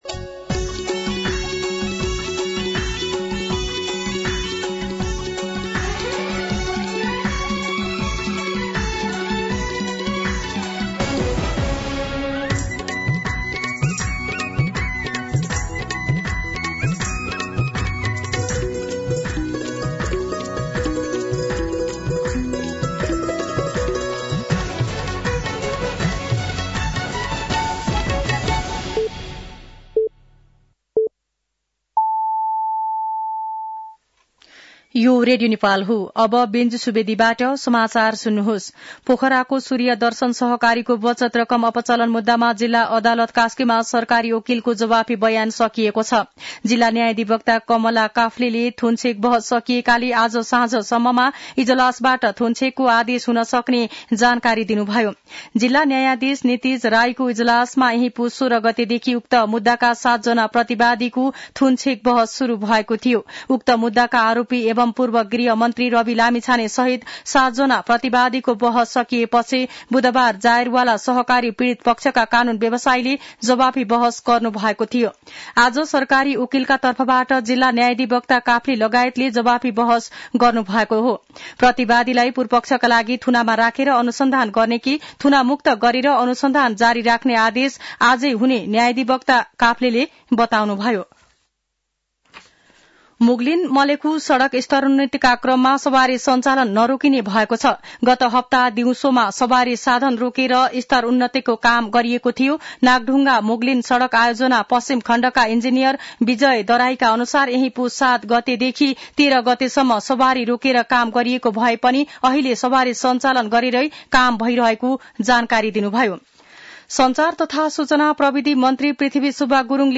दिउँसो ४ बजेको नेपाली समाचार : २६ पुष , २०८१
4-pm-nepali-news-3.mp3